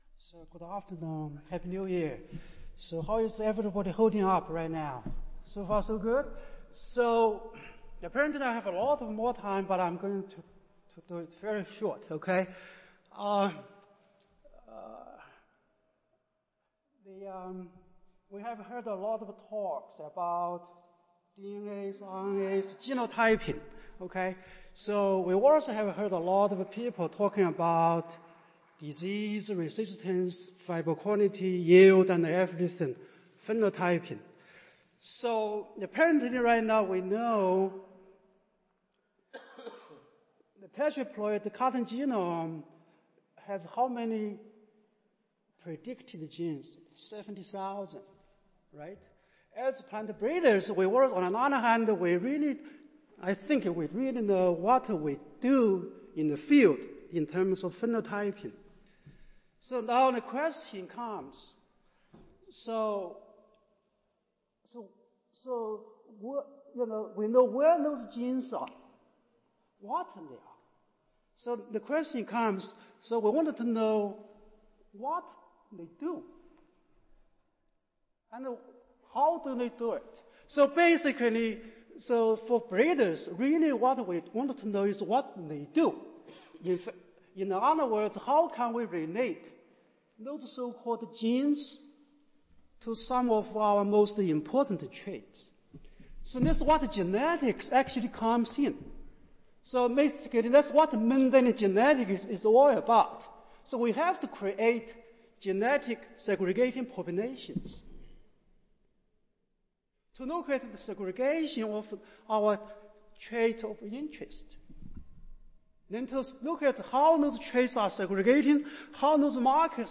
Lightning talks tied to a poster
Audio File Recorded Presentation